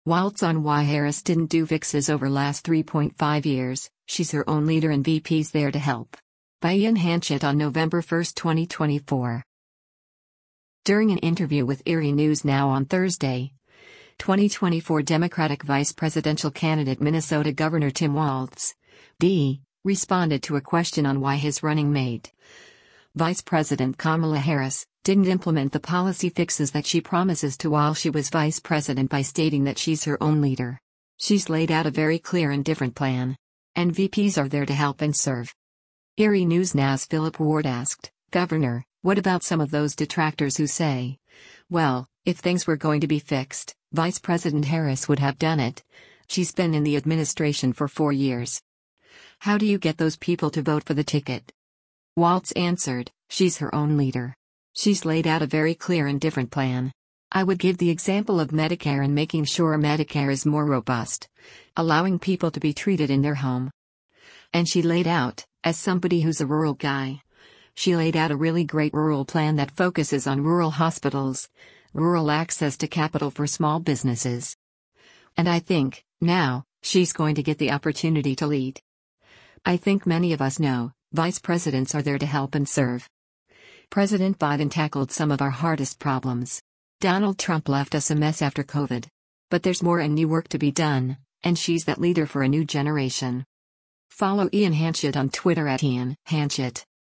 During an interview with Erie News Now on Thursday, 2024 Democratic vice presidential candidate Minnesota Gov. Tim Walz (D) responded to a question on why his running mate, Vice President Kamala Harris, didn’t implement the policy fixes that she promises to while she was Vice President by stating that “She’s her own leader.